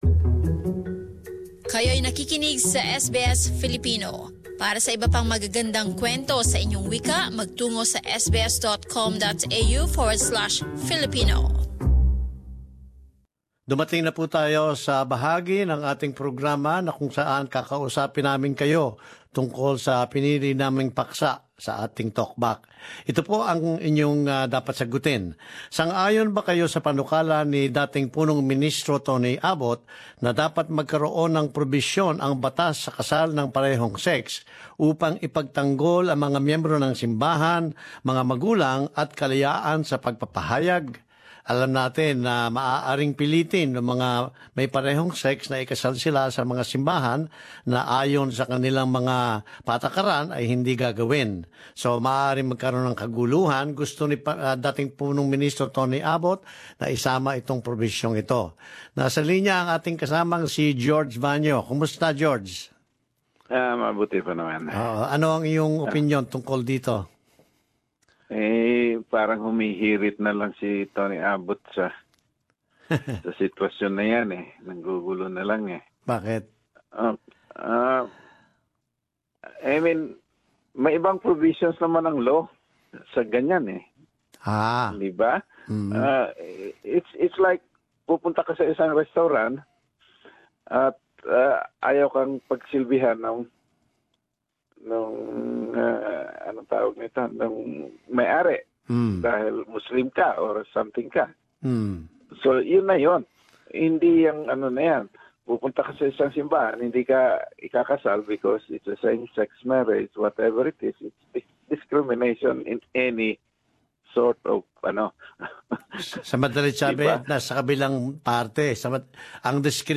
Talkback: Lehislasyon sa same sex marriage